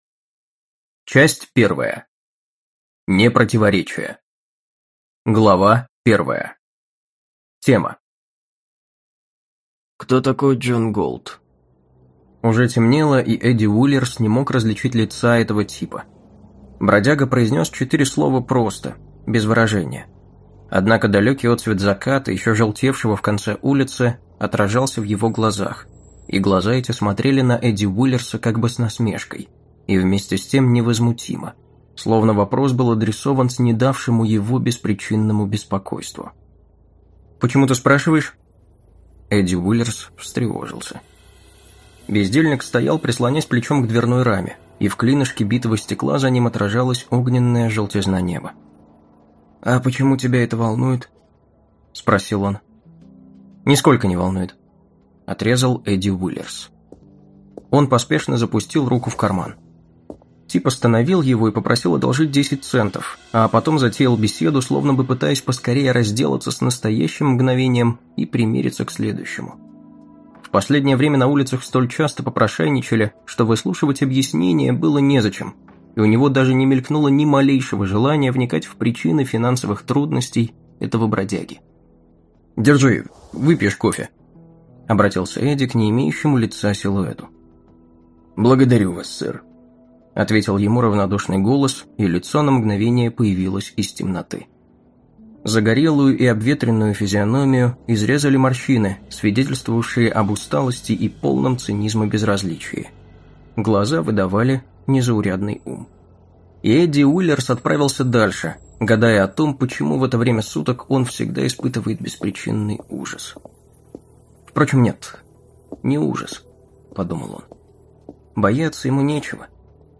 Аудиокнига Атлант расправил плечи | Библиотека аудиокниг